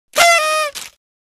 Party Horn Sound Effect